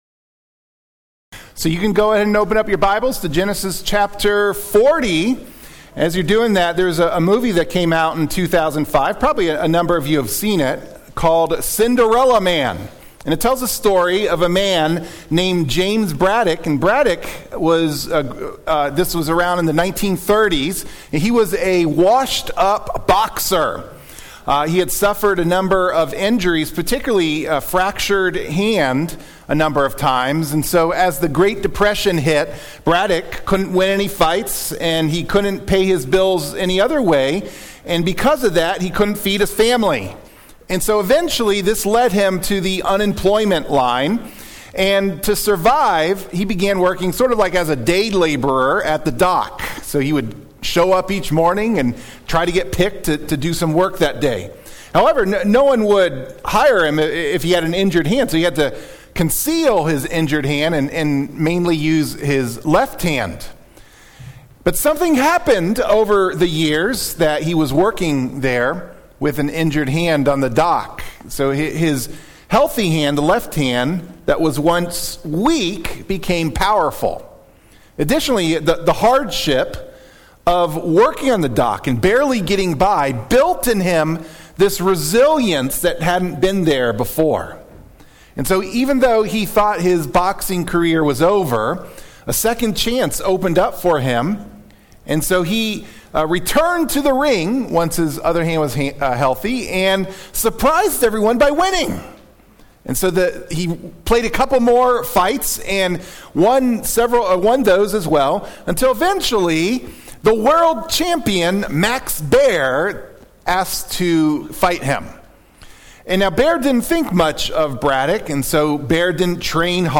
In this sermon on Genesis 40, we explore how God’s steadfast love (hesed) was with Joseph even in prison. While imprisoned, Joseph interprets dreams for Pharaoh’s officials, offering both hope and judgment.